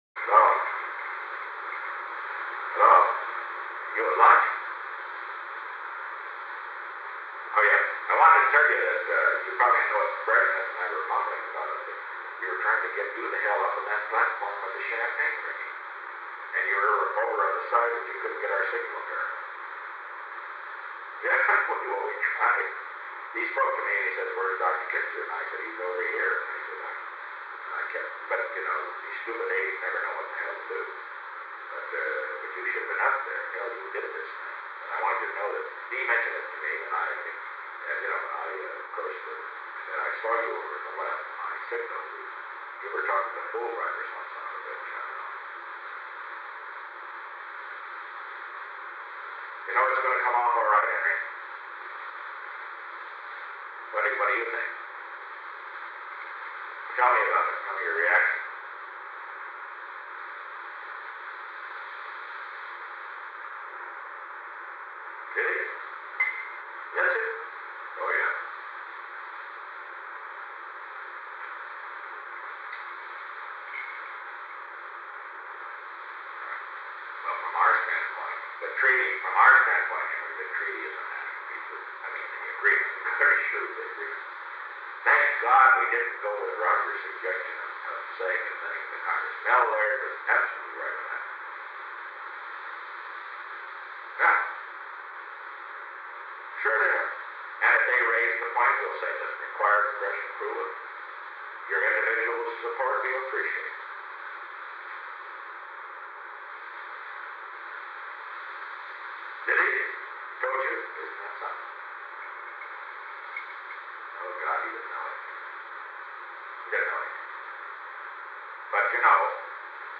Secret White House Tapes
Conversation No. 448-5
Location: Executive Office Building
The President talked with Henry A. Kissinger